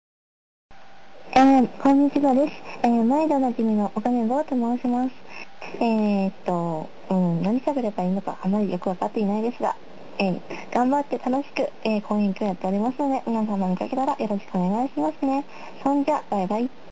自己紹介ボイス
サウンドレコーダーの不調で急遽ヤフーメッセンジャーから直接取った苦心ボイス。